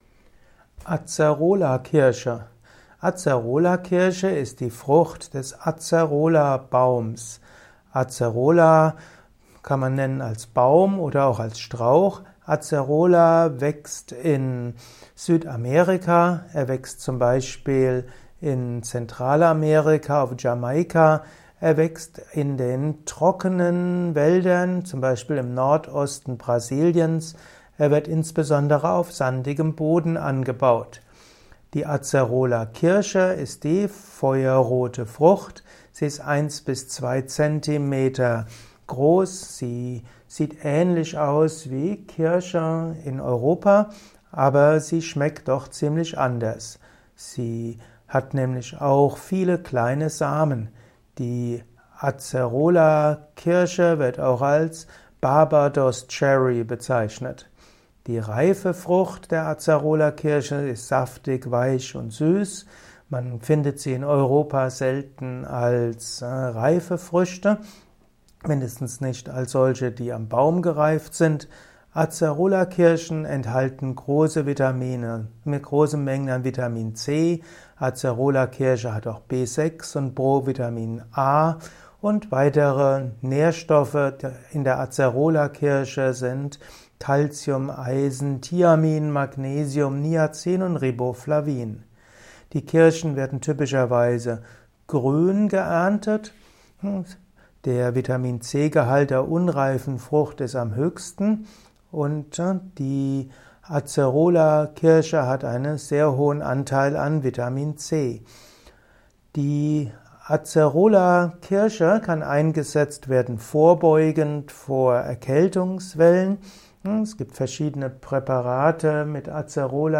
Acerolakirsche - Erfahre in diesem kurzen Improvisations-Vortrag mehr zum Thema Acerolakirsche.